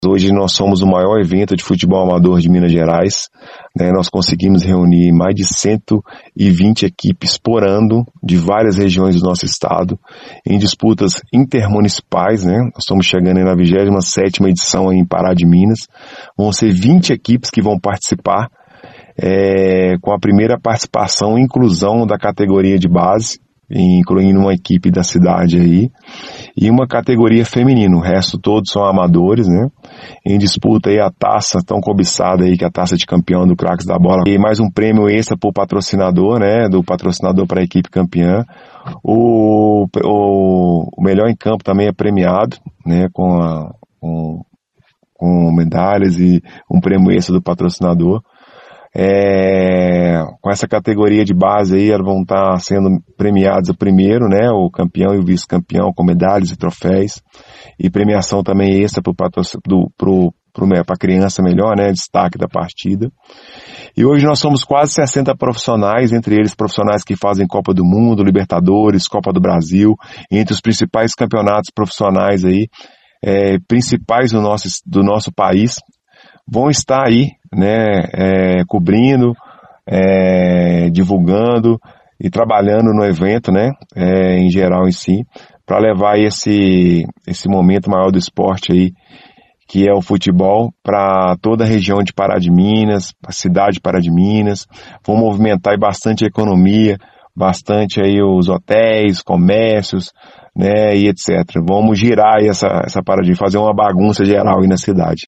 Em entrevista ao Jornal da Cidade